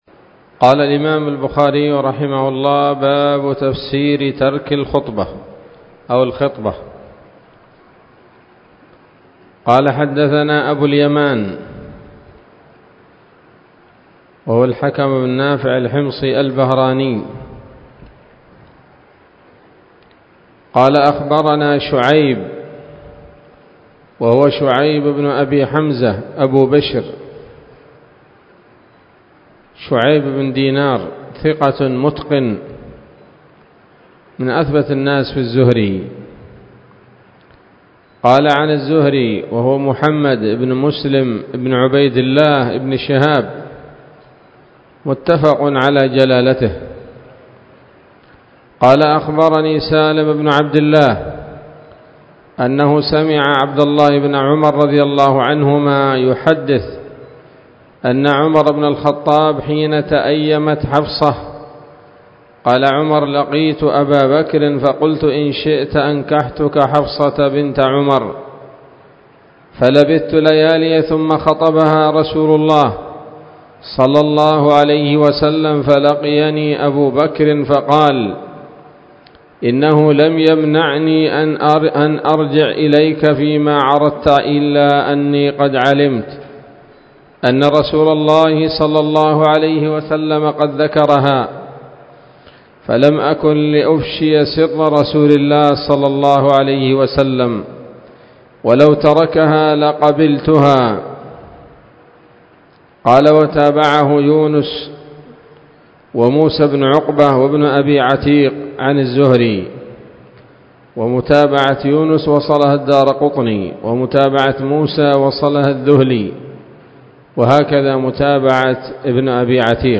الدرس الثاني والأربعون من كتاب النكاح من صحيح الإمام البخاري